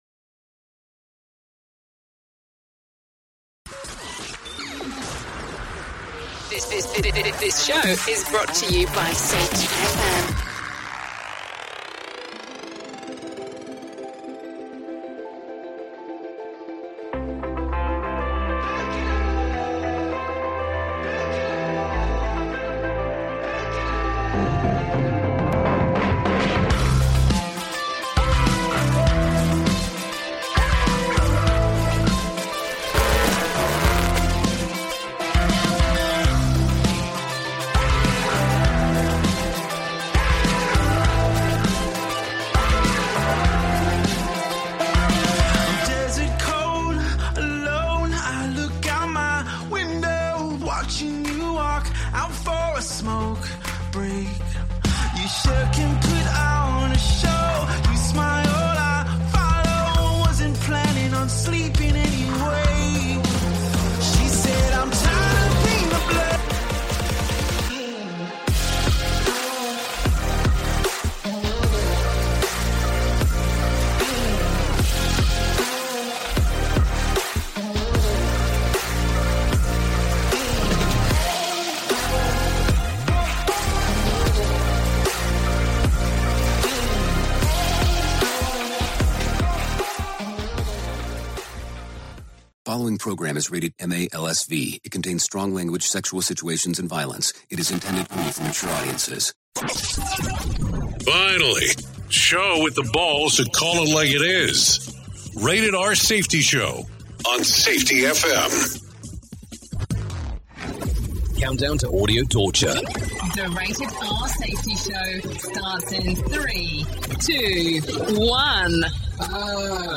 🎤 Expect nothing less than the most insightful and thought-provoking discussions on current events and crucial topics.